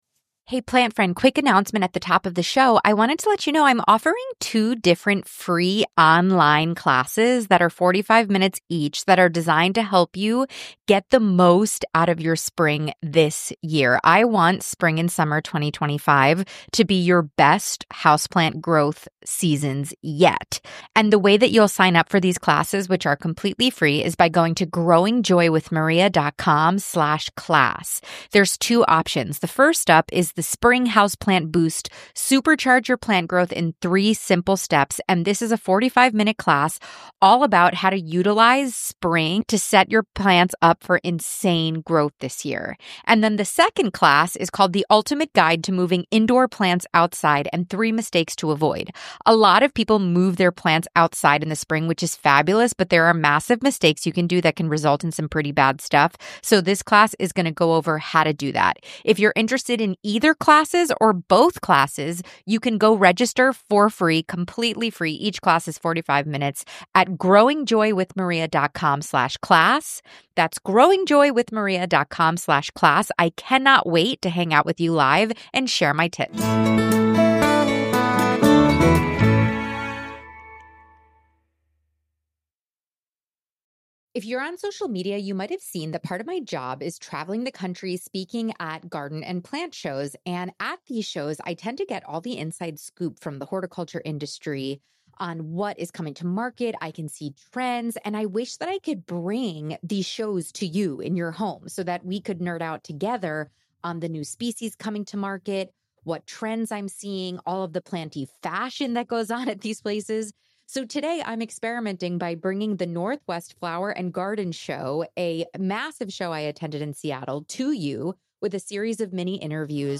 This episode is your virtual behind-the-scenes pass to the recent Northwest Flower & Garden Show in Seattle. I spent three days running around with my little mic, interviewing vendors, influencers, and anyone who would talk plants with me.